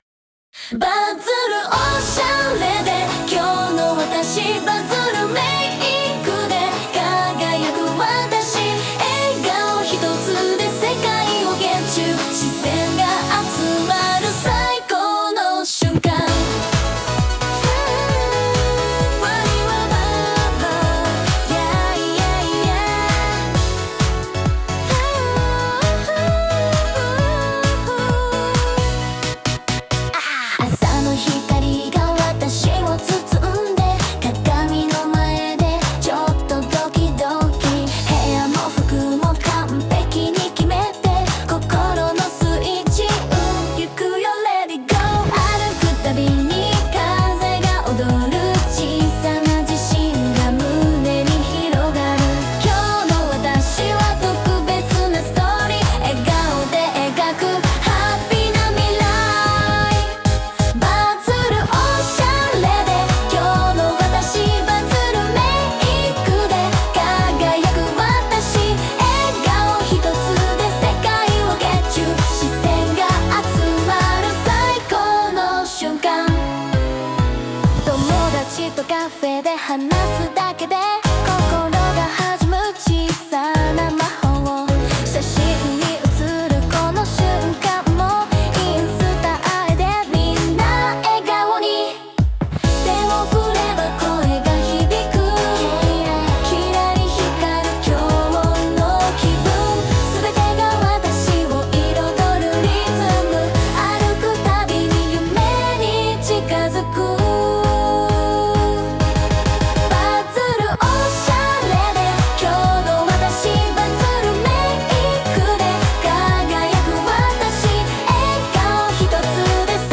明るい気持ちになれるハッピーソング！